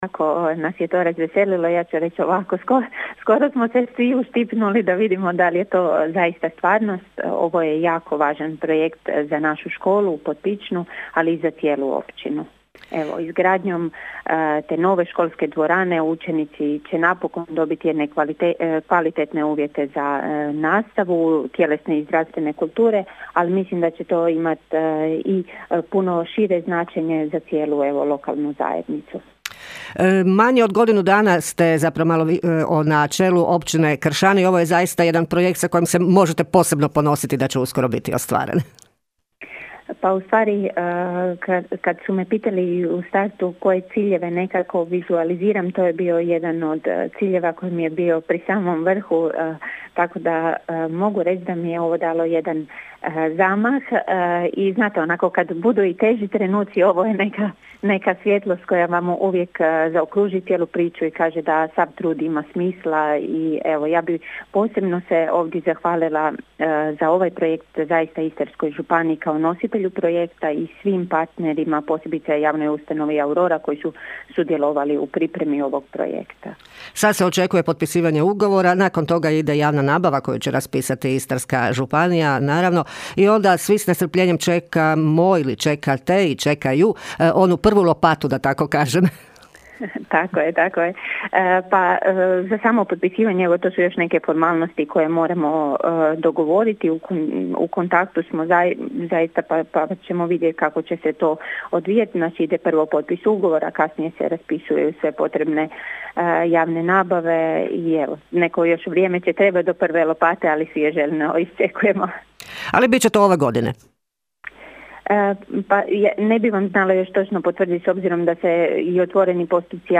Informacija da će se graditi dvorana jako je razveselila i općinsku načelnicu Kršana Anu Vuksan: (